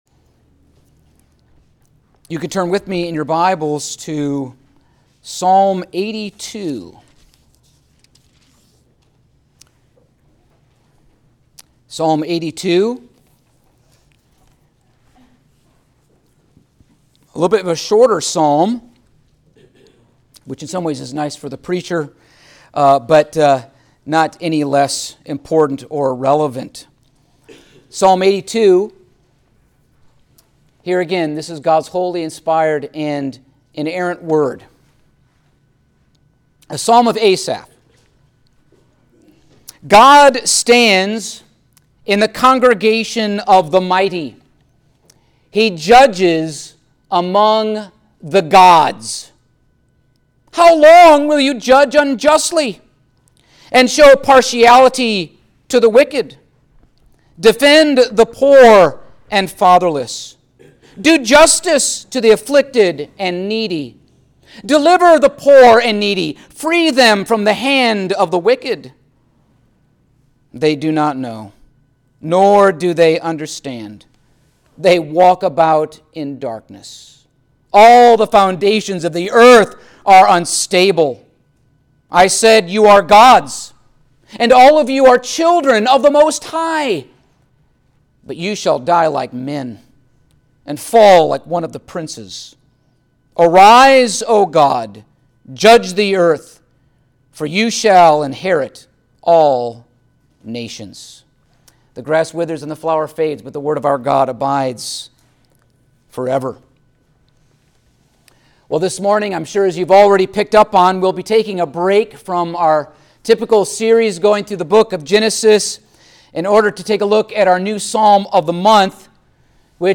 Passage: Psalm 82 Service Type: Sunday Morning